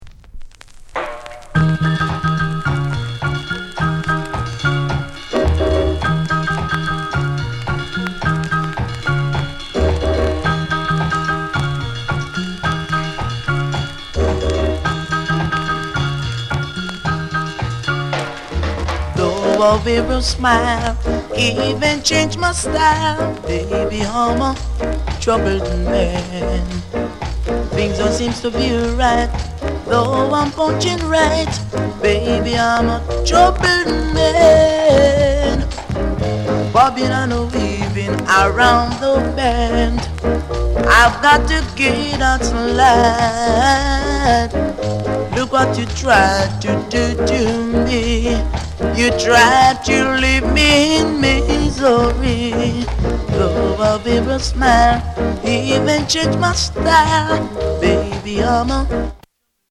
SOUND CONDITION A SIDE VG
ROCKSTEADY